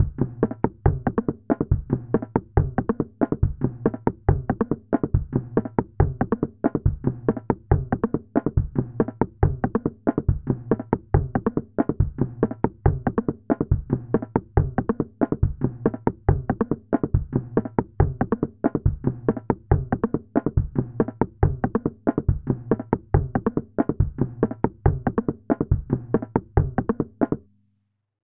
巴西打击乐器 " afoxé
描述：阿波克斯的声音，一种巴西打击乐器
标签： 仪器 节奏 巴西的 敲击
声道立体声